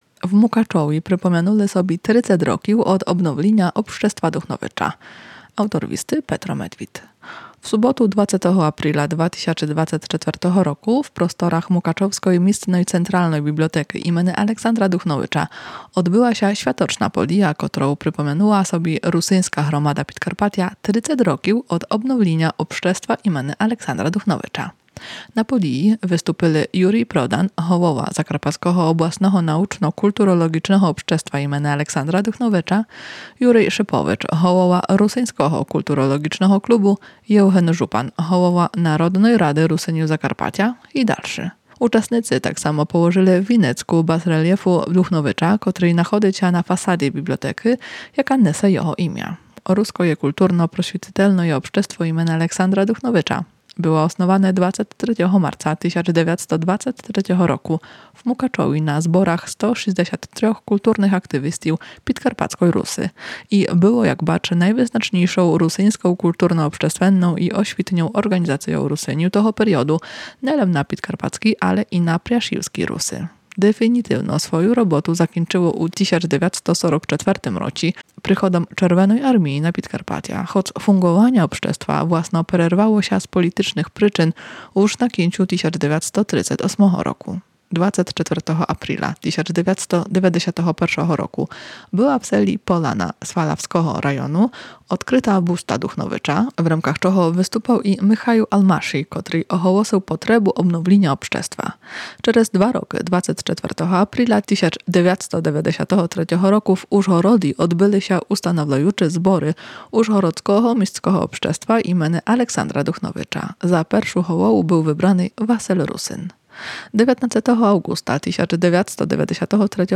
В суботу 20-го апріля 2024-го року в просторах Мукачовской містьской централной бібліотекы імени Александра Духновіча одбыла ся святочна подія, котров припомянула собі русиньска громада Підкарпатя 30 років од обновліня Общества імени Александра Духновіча.